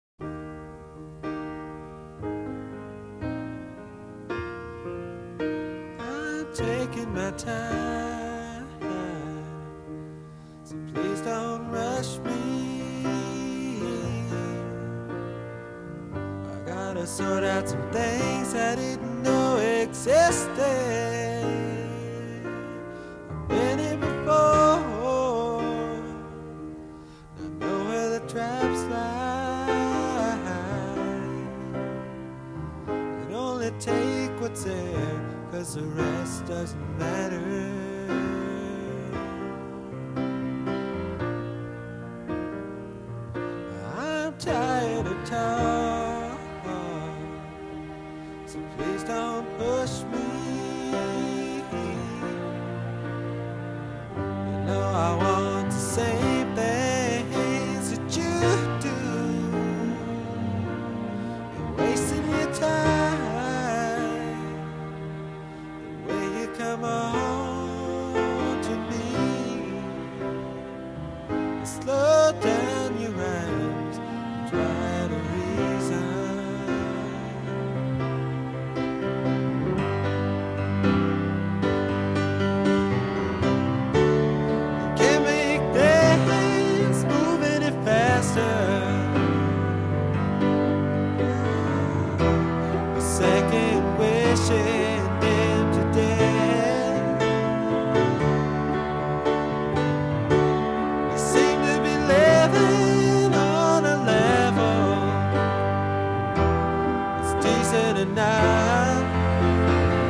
Allmusic.comAMG）五星滿點推薦，藍味十足的南方搖滾經典之作！